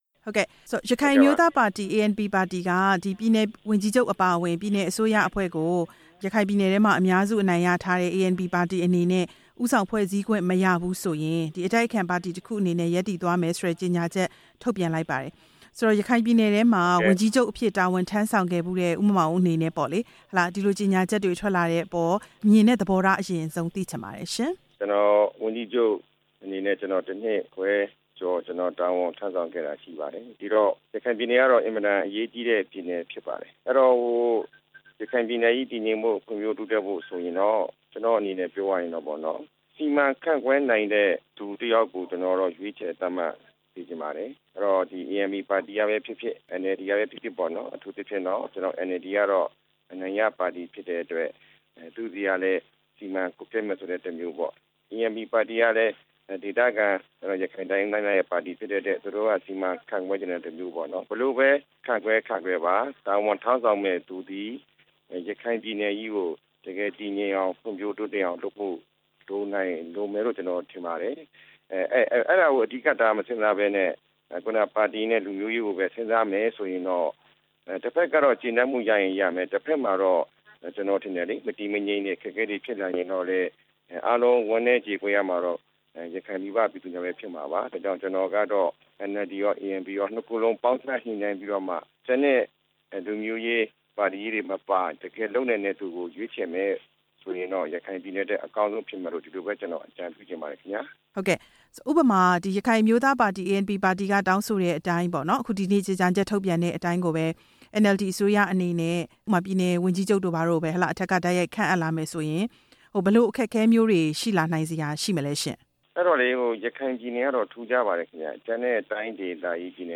ဝန်ကြီးချုပ်ဟောင်း ဦးမောင်မောင်အုန်း ကို မေးမြန်းချက် နားထောင်ရန်